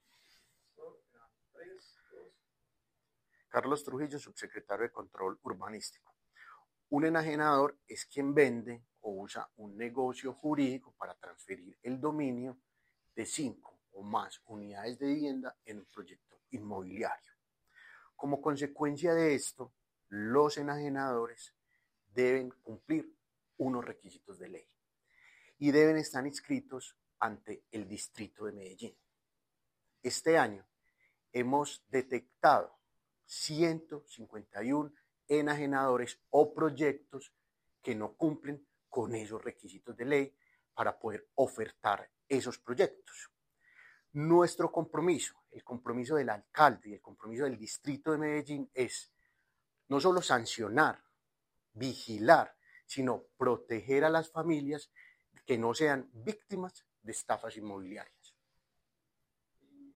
Declaraciones subsecretario de Control Urbanístico, Carlos Trujillo
Declaraciones-subsecretario-de-Control-Urbanistico-Carlos-Trujillo.mp3